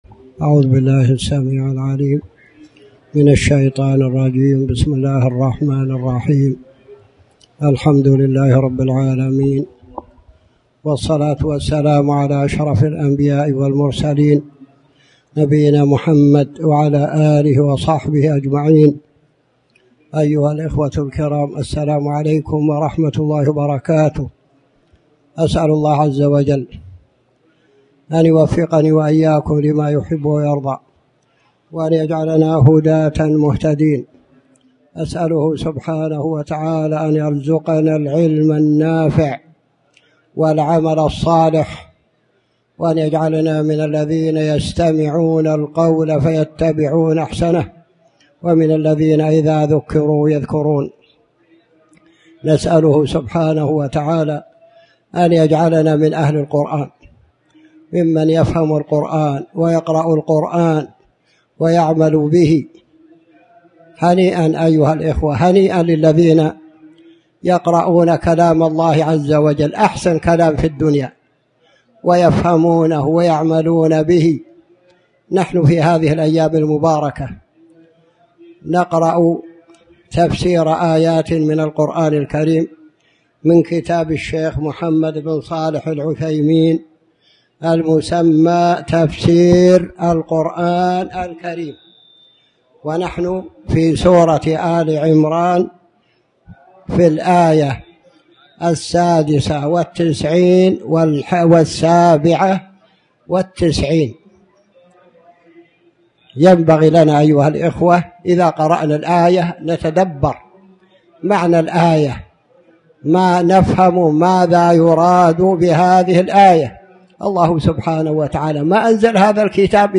تاريخ النشر ١١ ربيع الأول ١٤٣٩ هـ المكان: المسجد الحرام الشيخ